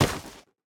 Minecraft Version Minecraft Version snapshot Latest Release | Latest Snapshot snapshot / assets / minecraft / sounds / block / nylium / step3.ogg Compare With Compare With Latest Release | Latest Snapshot
step3.ogg